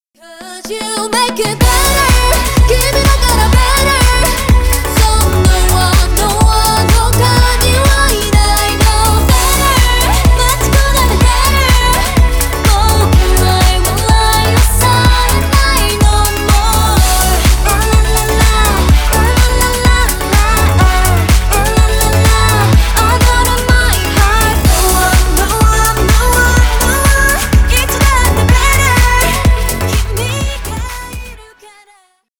Stereo
Корейские